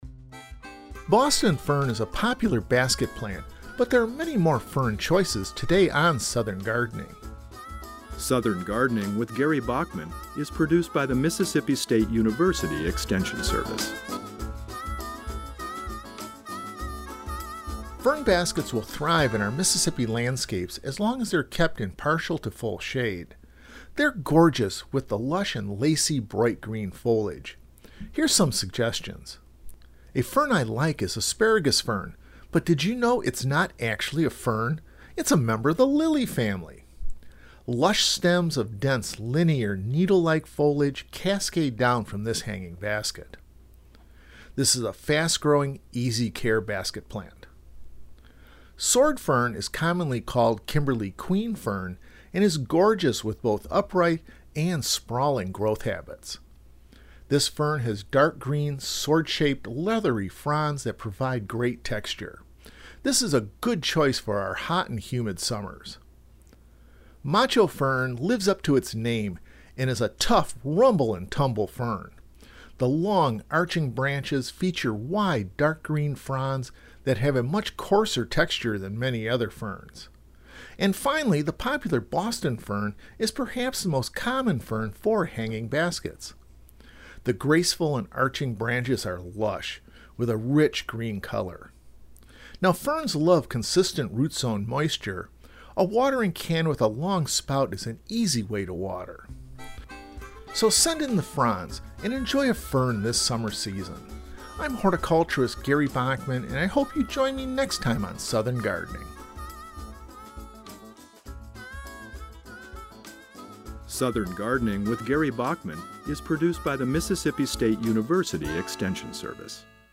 Host